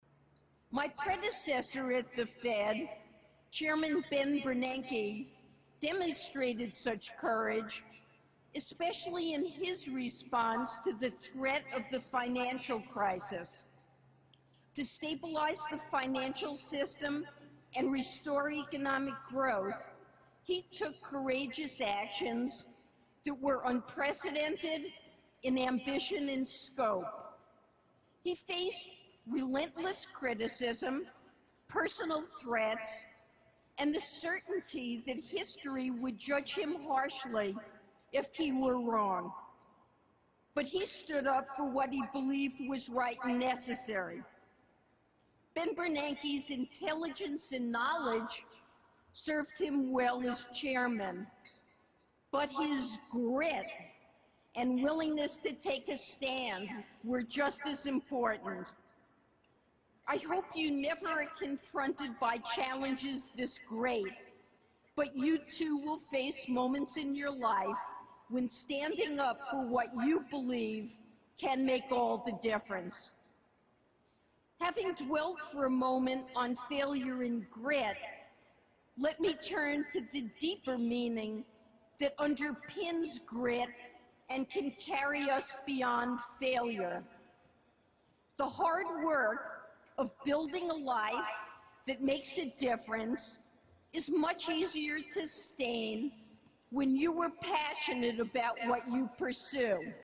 公众人物毕业演讲 第97期:耶伦纽约大学(7) 听力文件下载—在线英语听力室